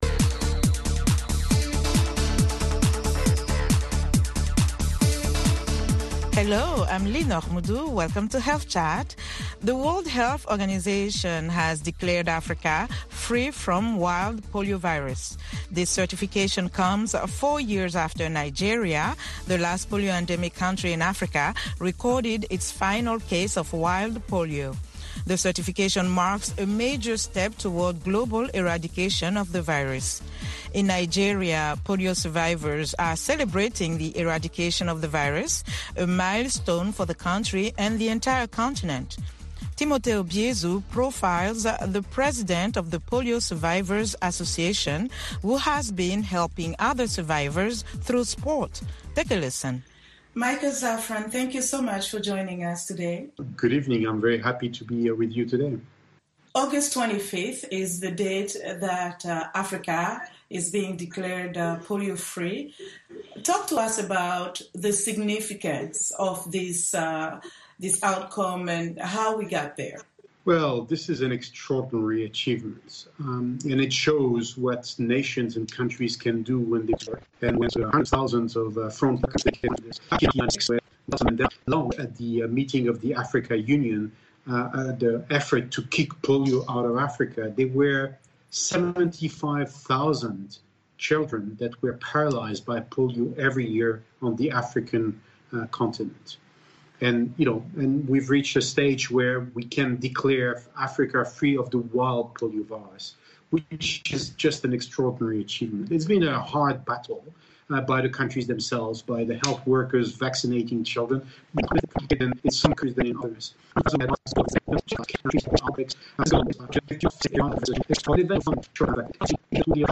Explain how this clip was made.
Health Chat is a live call-in program that addresses health issues of interest to Africa.